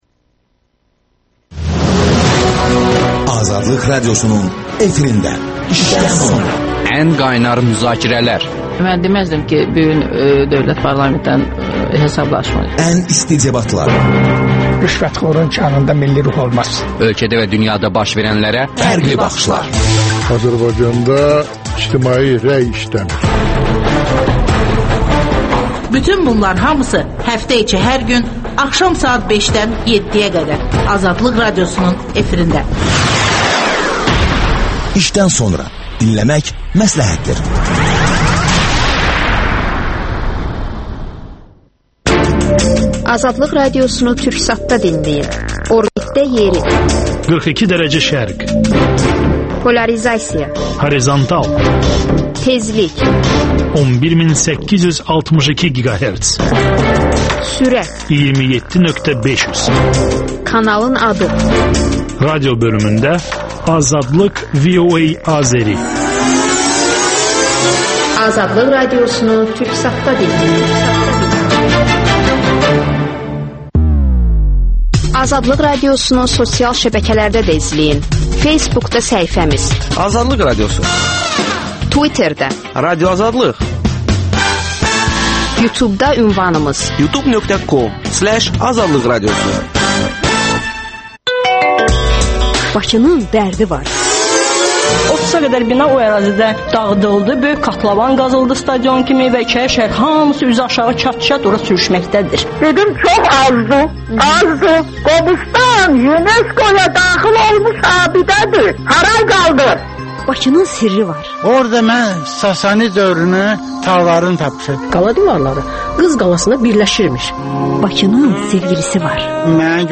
canlı yayımda suallara cavab verir.